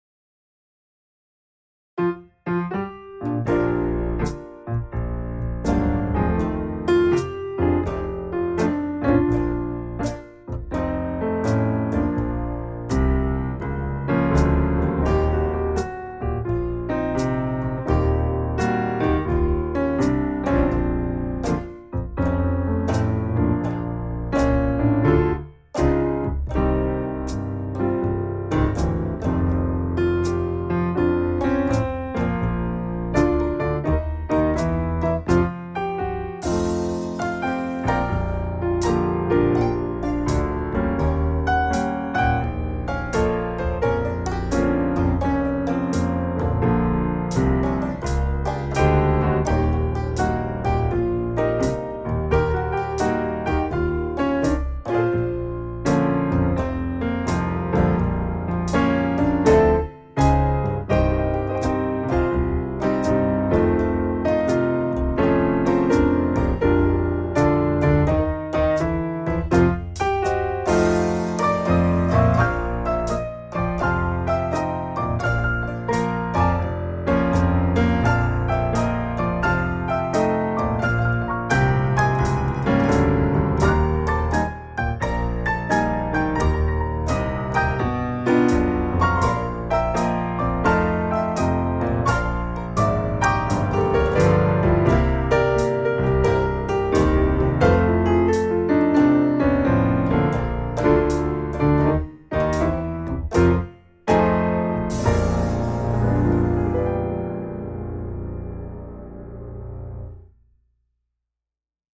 A very old blues
Recorded in AUM.